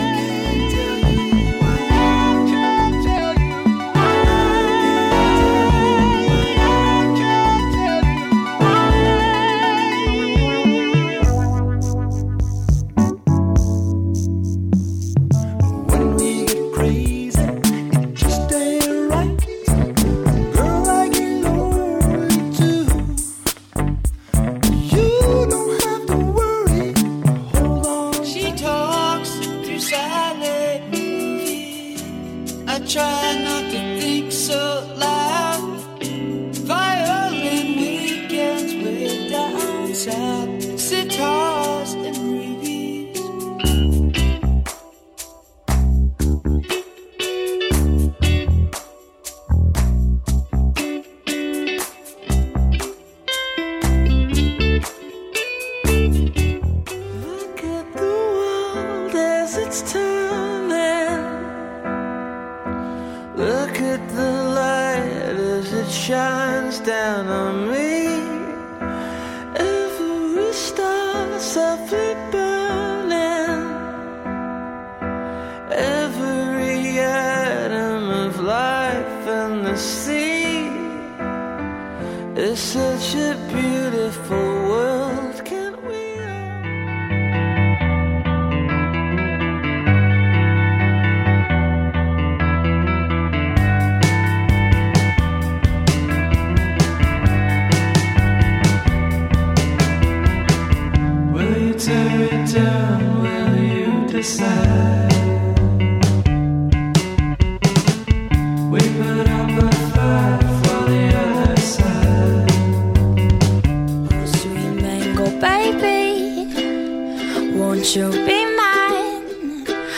Coffee Shop - Slow Tempo
Slow Tempo Easy Listening Playlist for Coffee Shops